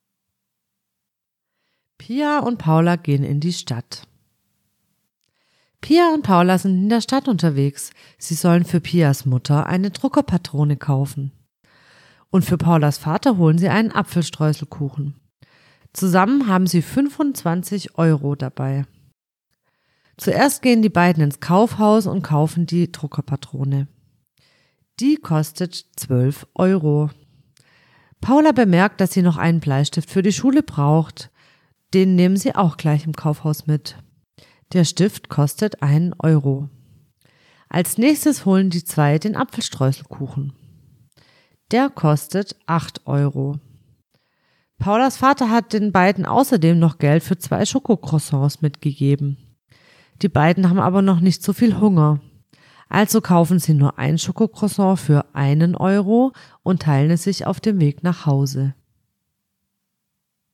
Optionale Tonaufnahme der Diktate (Rechtsklick, Ziel speichern unter...)